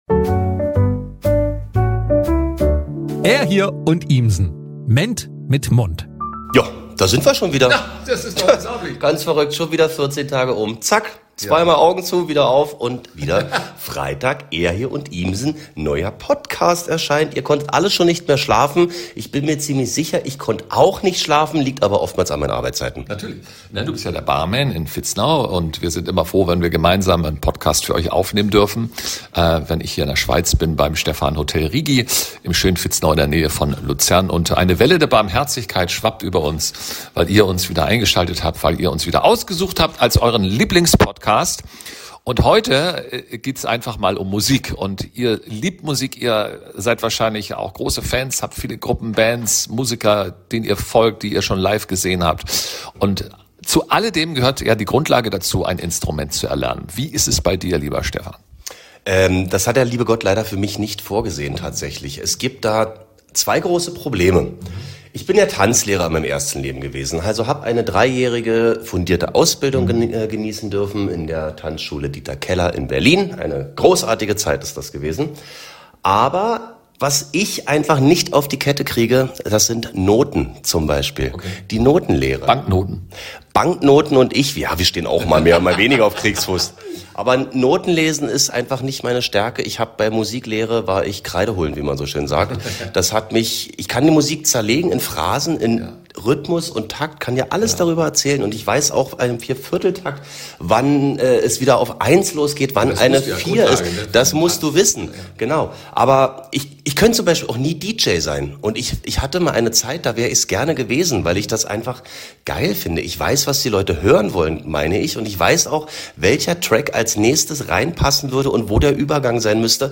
Dazu eine absolute Premiere: LIVE-MUSIK in eurem Podcast!